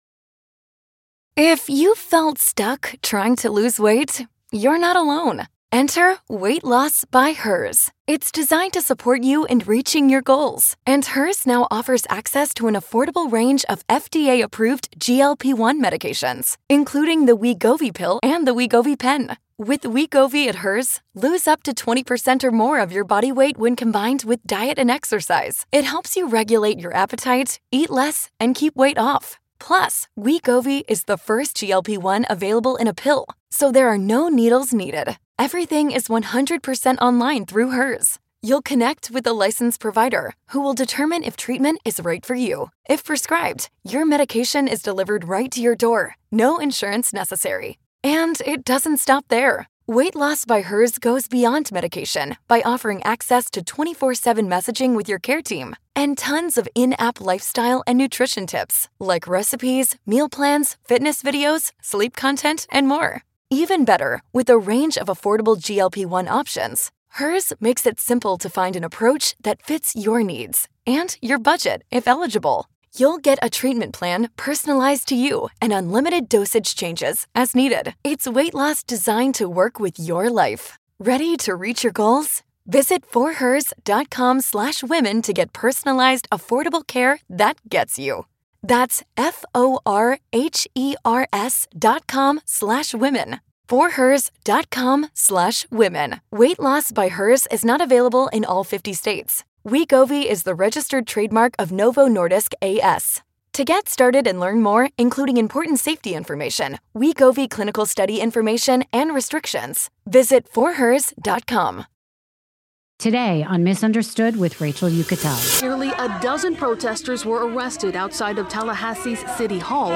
In this powerful conversation, Nikki opens up about the political battles most people never see—the clashes with Ron DeSantis, the controversy around Alligator Alcatraz, and why the so-called “Big Beautiful Bill” isn’t what it seems. She also shares her strategy for rebuilding the Florida Democratic Party, why local elections matter more than most realize, and what she wants young women to know about power, protest, and persistence.